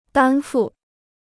担负 (dān fù): to shoulder; to bear.